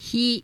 Both can be written in two strokes, sometimes one for hiragana, and both are phonemically /hi/ although for phonological reasons, the actual pronunciation is [çi]
The pronunciation of the voiceless palatal fricative [ç] is similar to that of the English word hue [çuː] for some speakers.